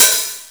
• Shiny Open Hi Hat B Key 29.wav
Royality free open hat sample tuned to the B note. Loudest frequency: 8013Hz
shiny-open-hi-hat-b-key-29-xtg.wav